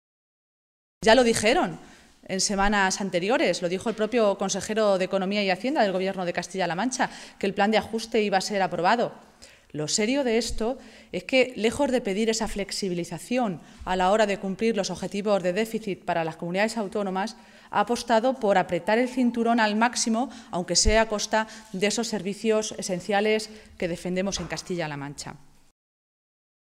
Cristina Maestre, portavoz de la Ejecutiva Regional del PSOE de Castilla-La Mancha
Cortes de audio de la rueda de prensa